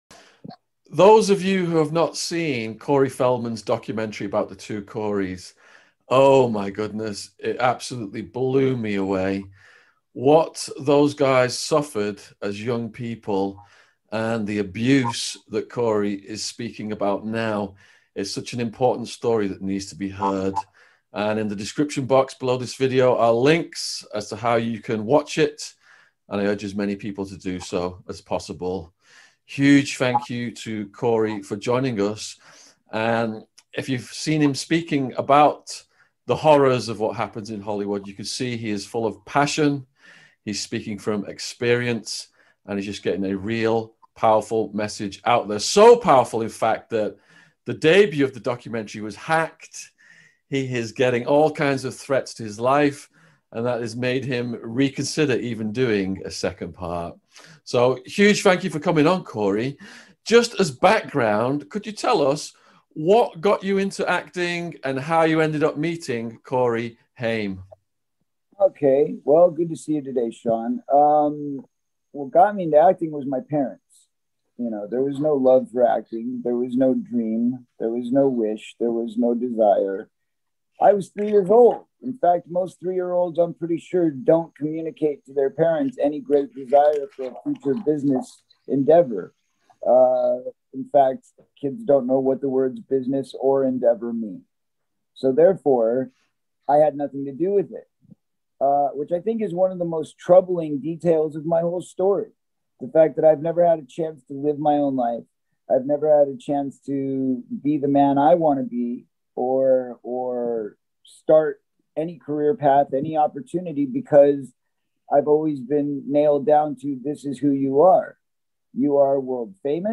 Corey Feldman Interview Exposing Hollywood | Podcast 944